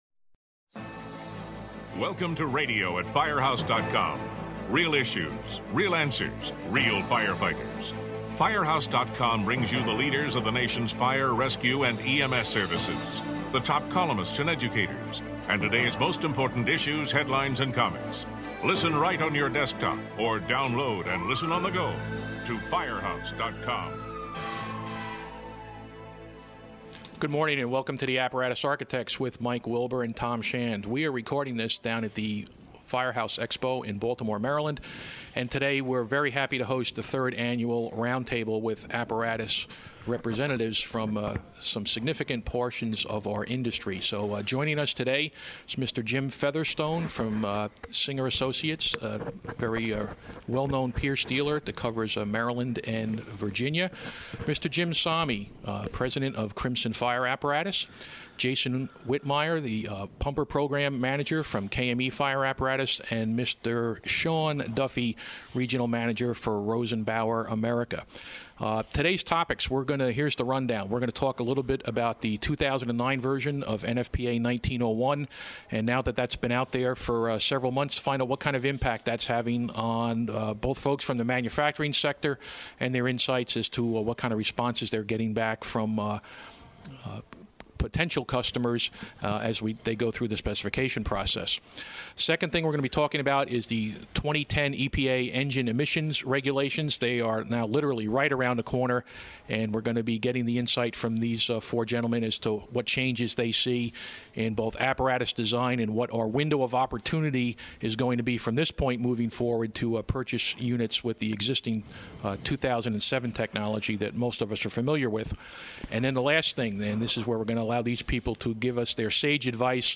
The panel explores the impact of future apparatus design and cost based on the stringent 2010 EPA engine emission standards that take effects in just a short time. This podcast was recorded at Firehouse Expo in July.